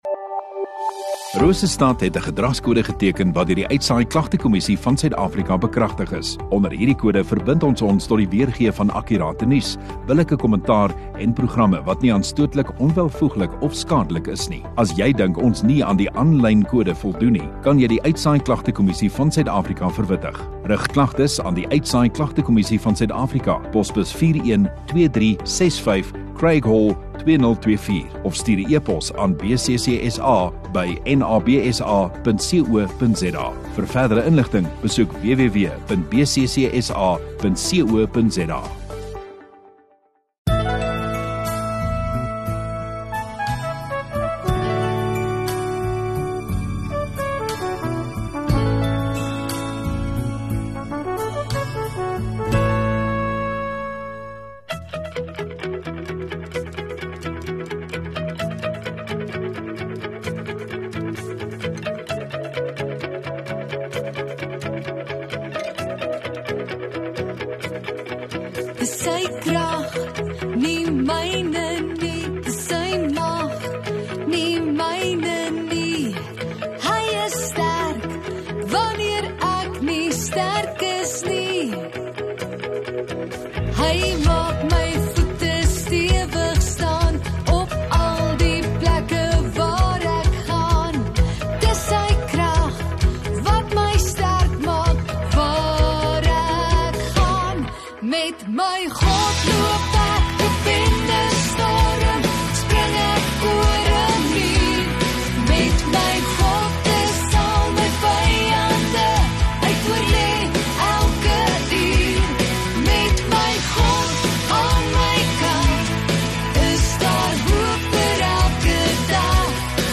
10 Aug Sondagoggend Erediens